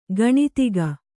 ♪ gaṇitiga